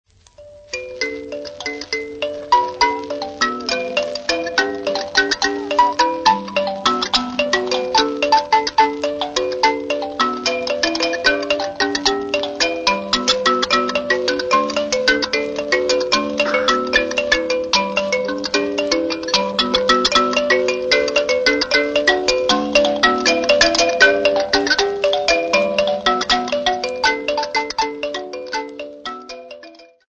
Folk Music
Field recordings
Africa Mozambique city not specified f-mz
sound recording-musical
Indigenous music